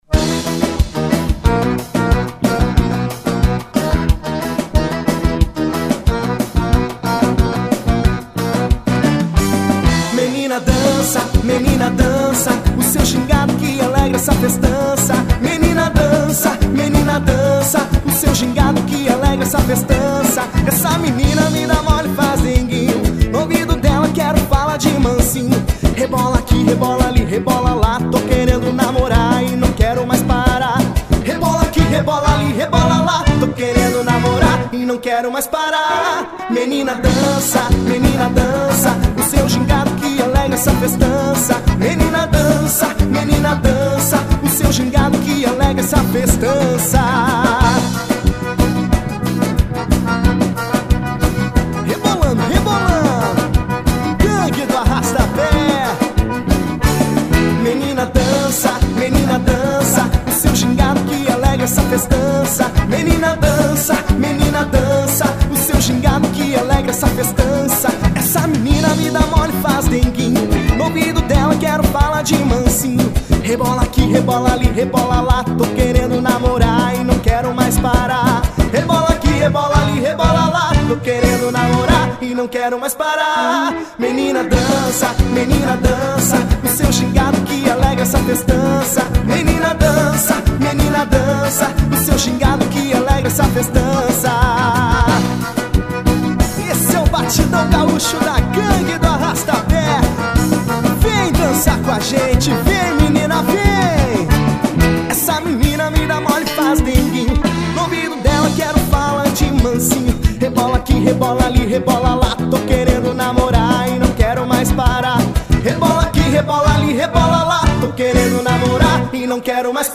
Batidão Gaúcho.